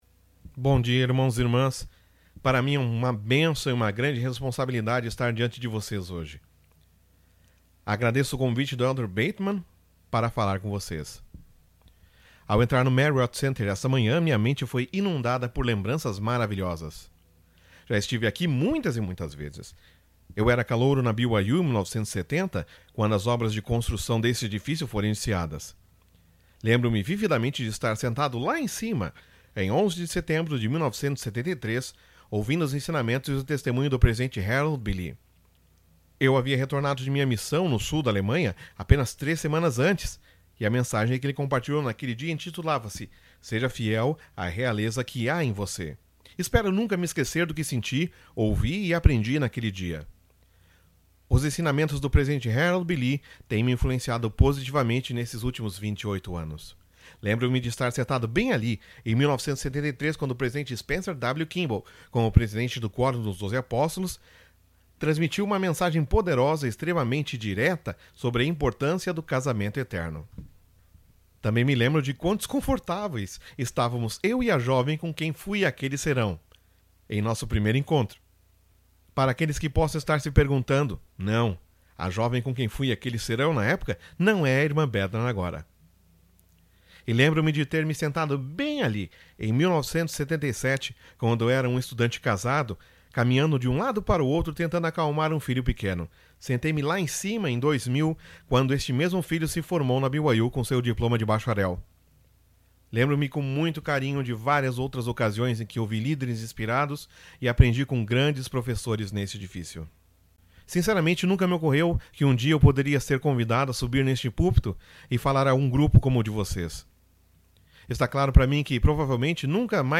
Audio recording of “Com a força do Senhor” by David A. Bednar